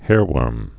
(hârwûrm)